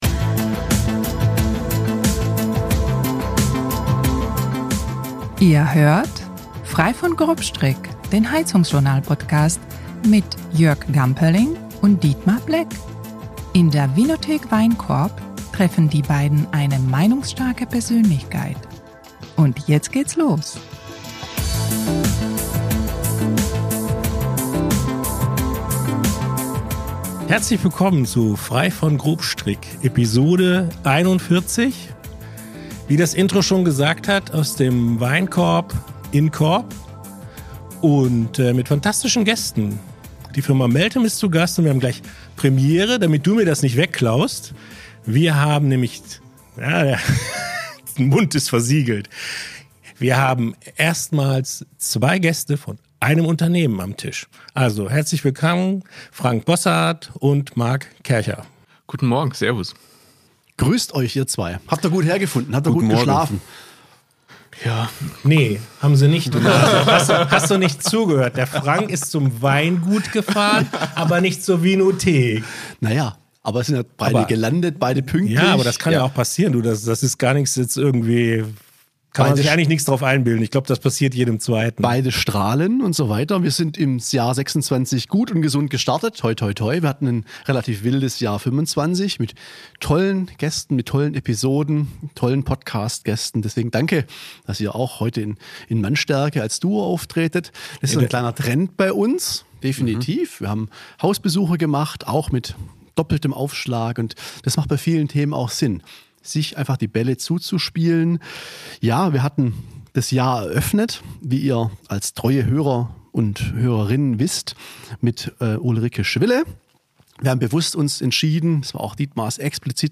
Locker bis launig, meinungsstark und informativ, das ist Frei von Grobstrick, der HeizungsJournal- Podcast.